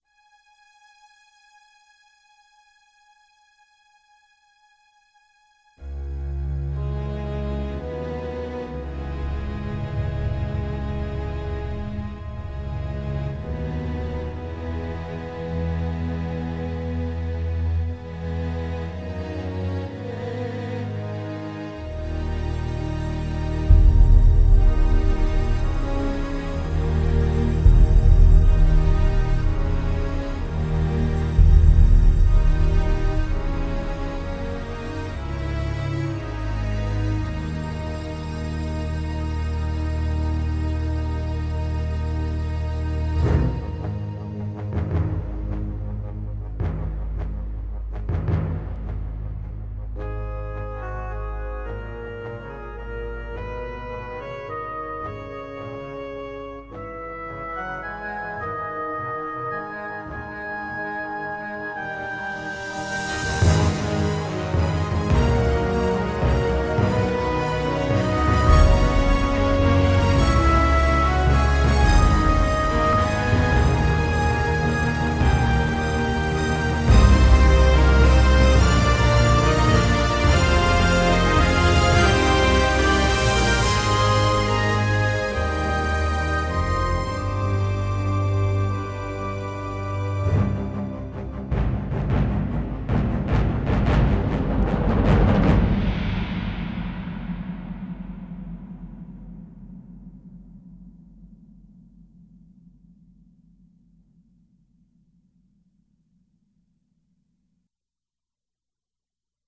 the score